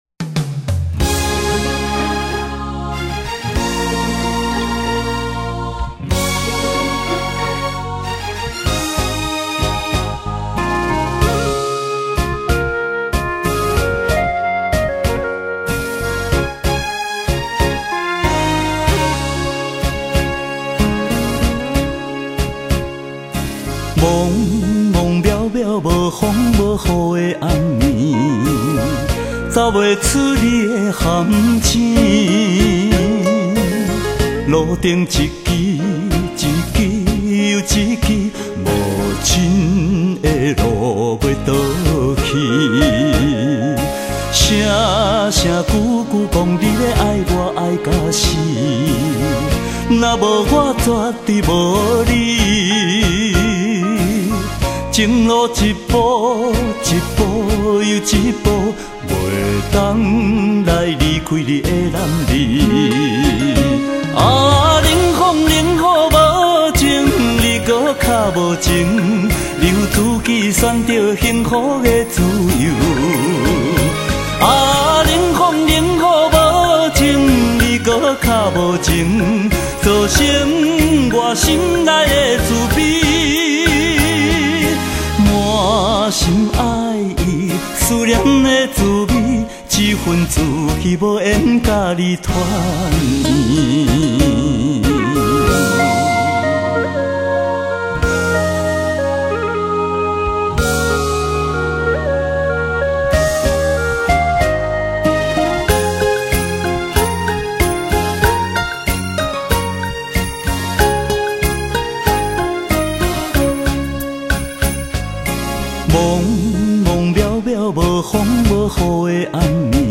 奖』，他独树一格的演唱风格，深受日本演歌影响，歌唱特色明显
、稳定性佳的演歌力道、再加上男性的特有哭腔，在台湾歌坛来说
闽南语歌曲